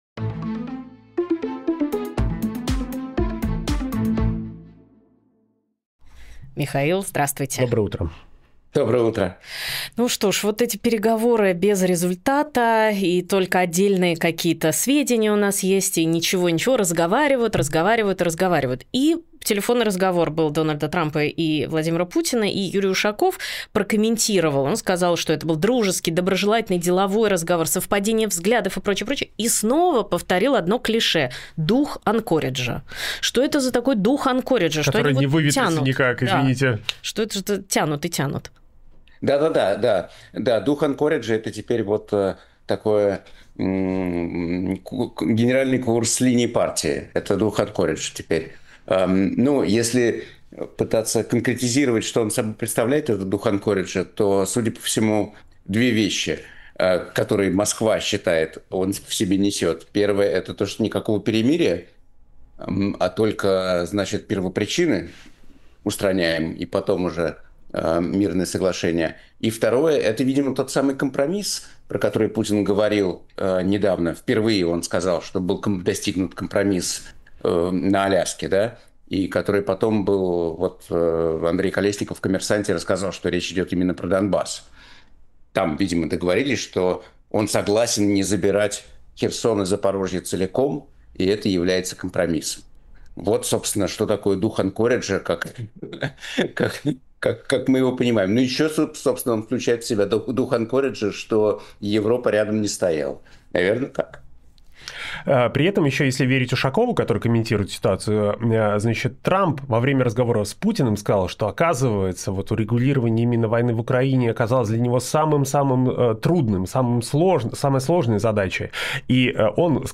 Фрагмент эфира от 29.12.25
Михаил Фишман журналист, ведущий «Дождя»